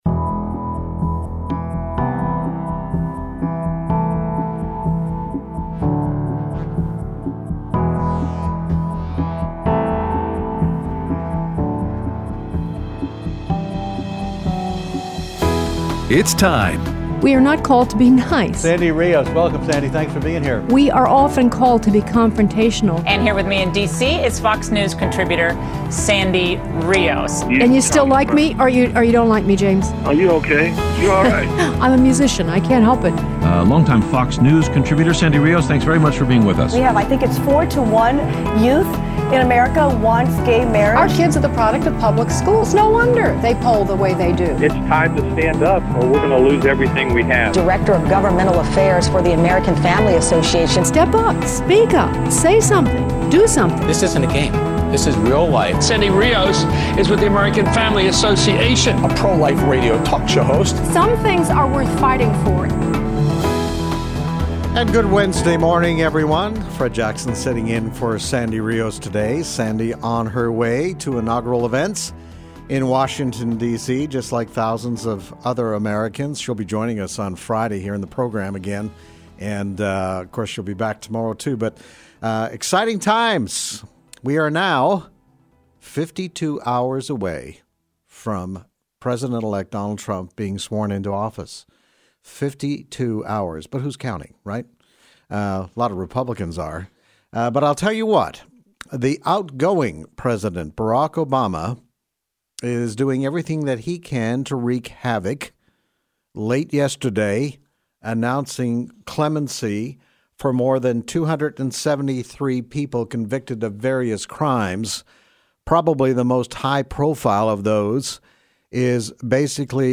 Bradley Manning Commuted, Interview with Gary Bauer, and Your Phone Calls